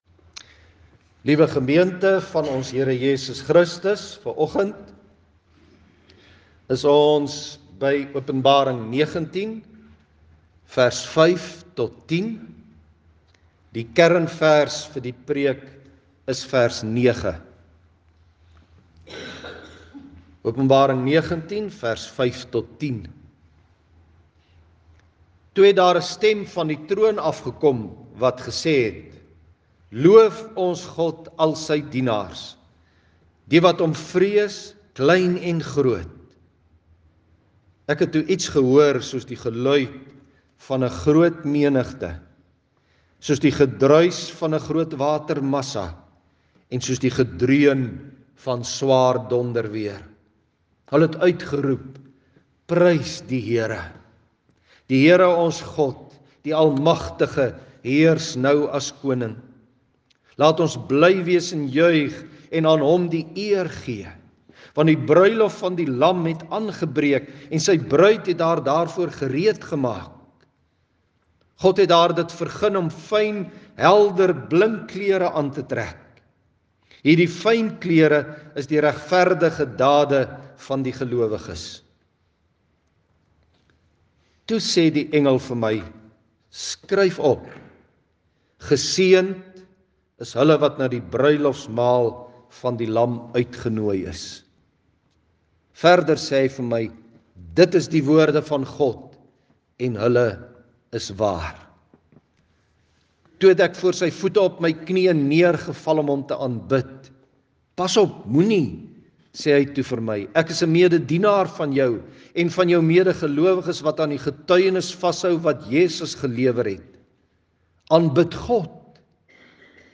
Klankbaan